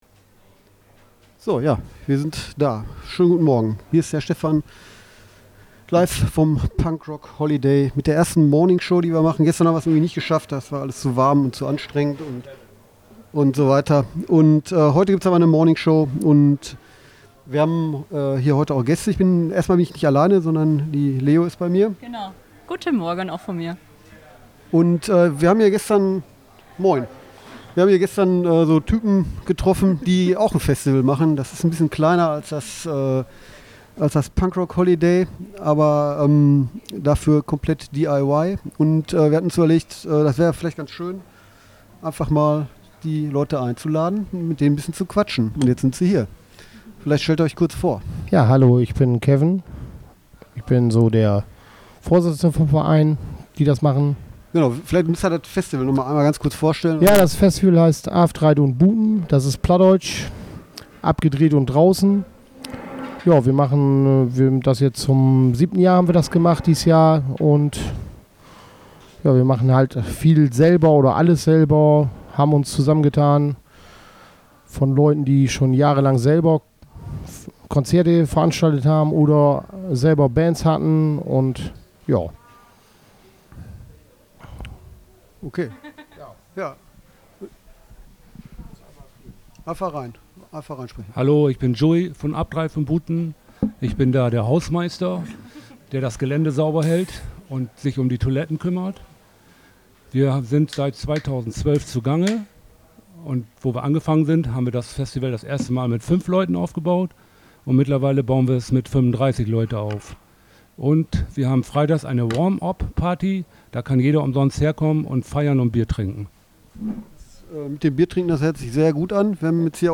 morning-show-vom-punk-rock-holiday-1-8-zum-afdreiht-un-buten-festival-mmp.mp3